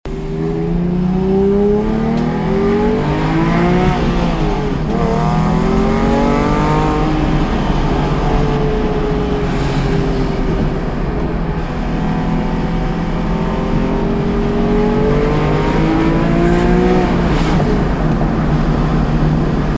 For this sound comparison between the 991 and 997, we’ll focus on the sound from inside the cabin. I’ve included a few recordings below; the sound is predominately the exhaust.
I used an Android App called ASR with my phone secured in a cradle mounted on the inside of the front windshield. The driver and passenger windows were open for all four recordings.
Both cars were in Sport Plus mode.
The run in the 997 starts in first, you hear a shift to second, and then a shift to third near the end of the recording. 997 Roundabout The run in the 991 starts in first, you hear the shift to second and third, then a downshift to second.
The 991 reved-up in 2nd gear is putting out more sound at the end of the run than the 997 in third. 991 Roundabout In the second set of recordings, I’m turning onto a street that is level for the length of the run.
Sounds-997-level-street.mp3